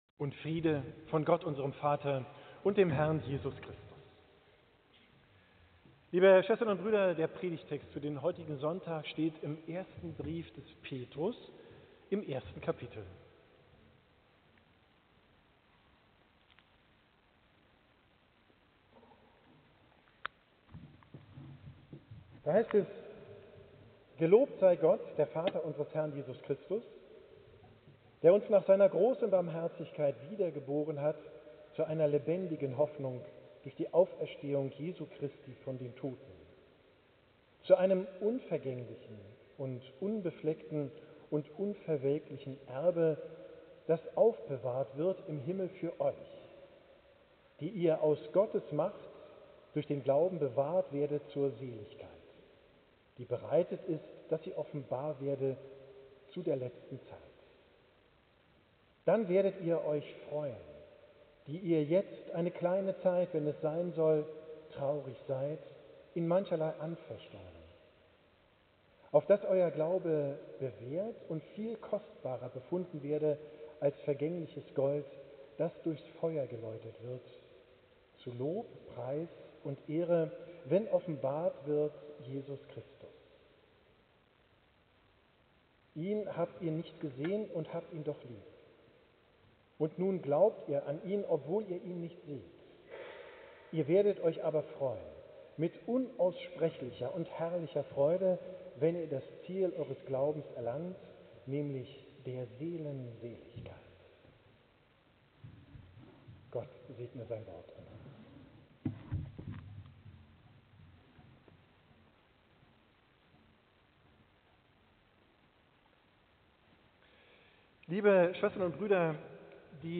Predigt vom Sonntag Quasimodogeniti, 27.